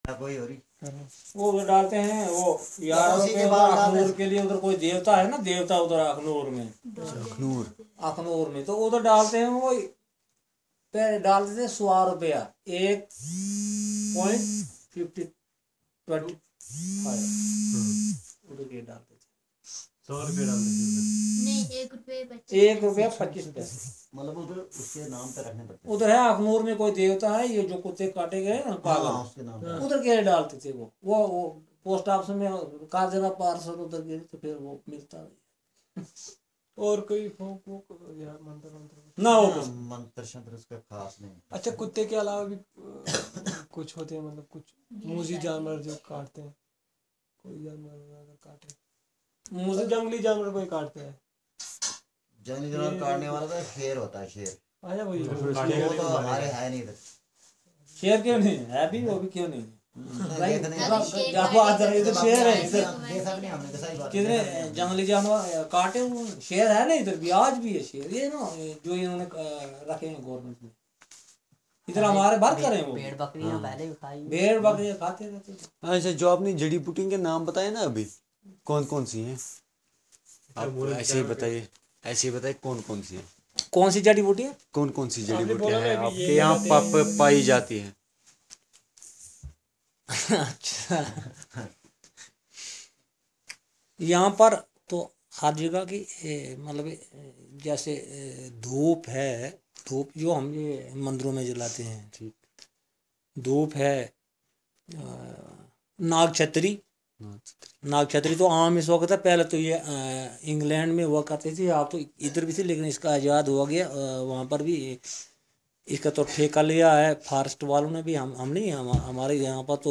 Conversation about medicines - Part 2